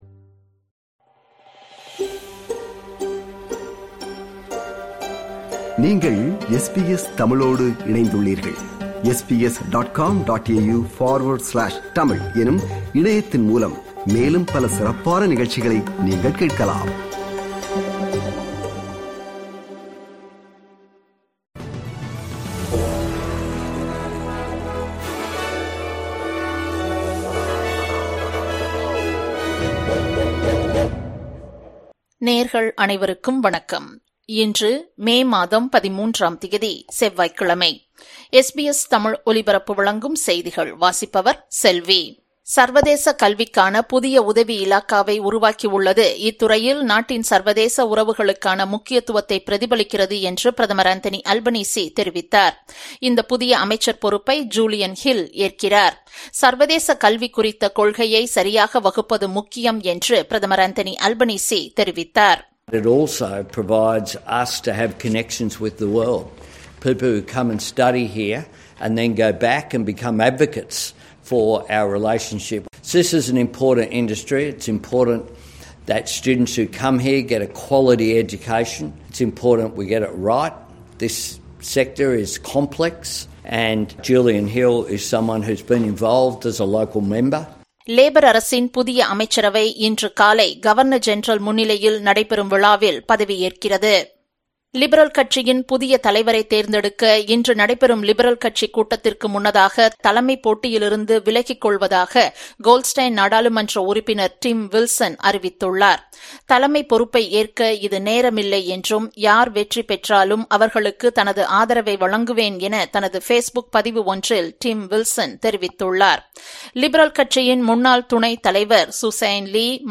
SBS தமிழ் ஒலிபரப்பின் இன்றைய (செவ்வாய்க்கிழமை 13/05/2025) செய்திகள்.